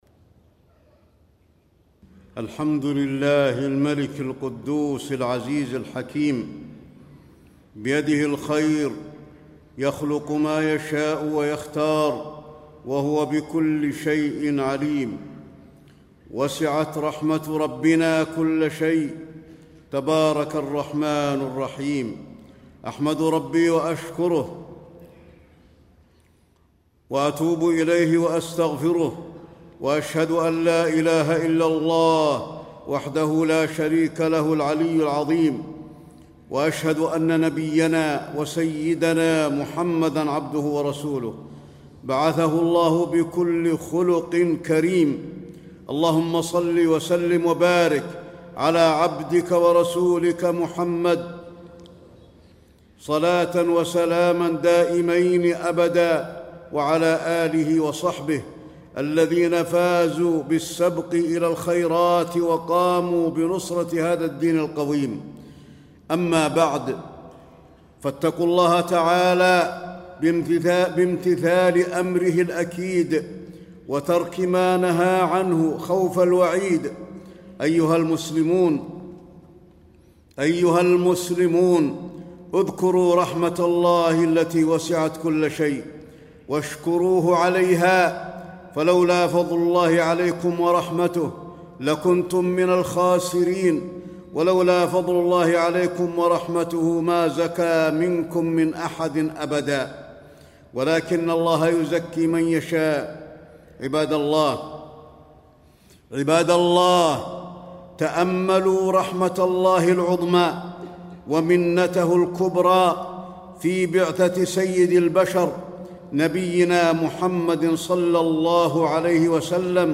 تاريخ النشر ٢٦ محرم ١٤٣٥ هـ المكان: المسجد النبوي الشيخ: فضيلة الشيخ د. علي بن عبدالرحمن الحذيفي فضيلة الشيخ د. علي بن عبدالرحمن الحذيفي صفات النبي صلى الله عليه وسلم